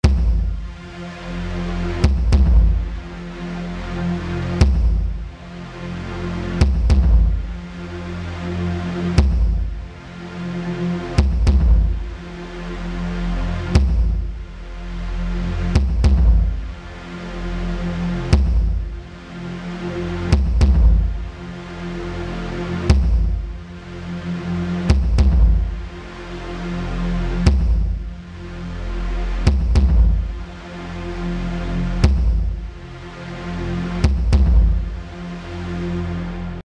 Eine andere beliebte Spielart ist die Rhythmisierung eines Flächensounds, den man durch einen Beat/Percussion-Track komprimiert.
Über ein Absenken des Thresholds (auf -30 dB) und eine Verlängerung der Releasezeiten kann man den Effekt verstärken: